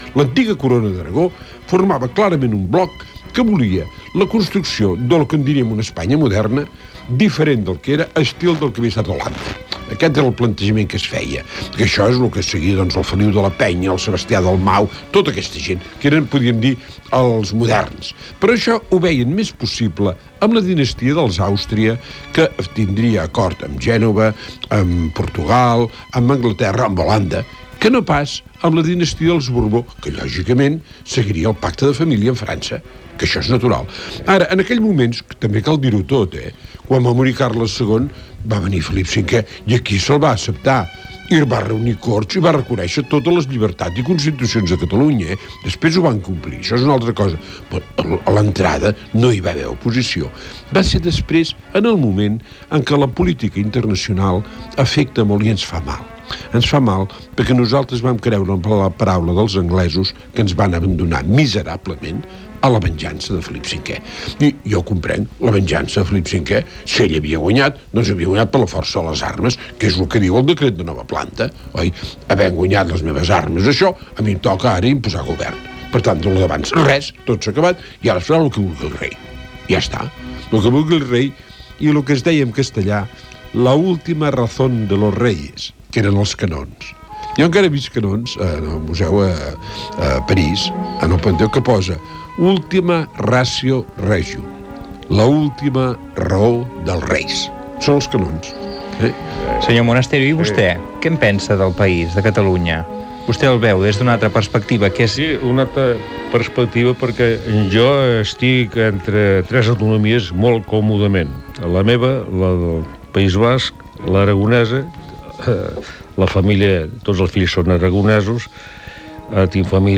Conversa